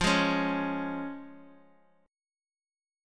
Listen to Fm#5 strummed